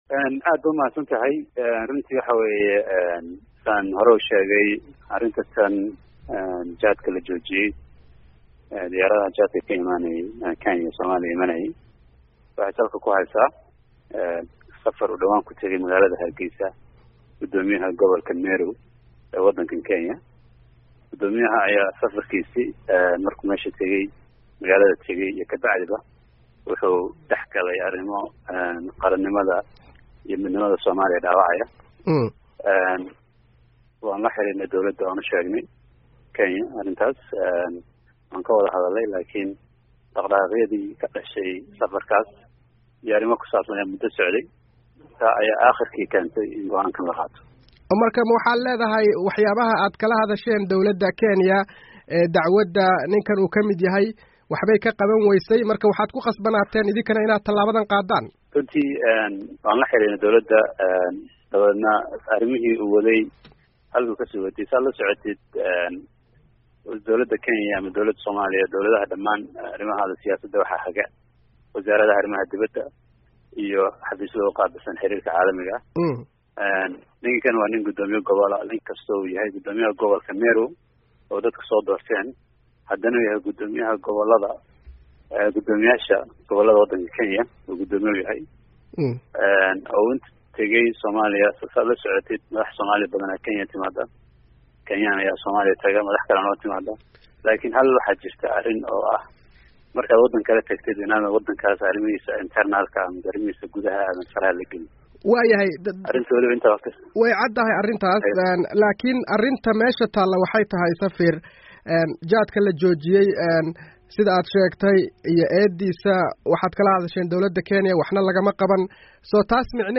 Wareysi: Safiirka Soomaaliya ee Kenya